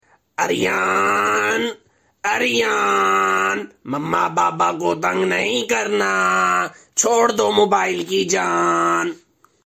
Goat calling Aryan name funny sound effects free download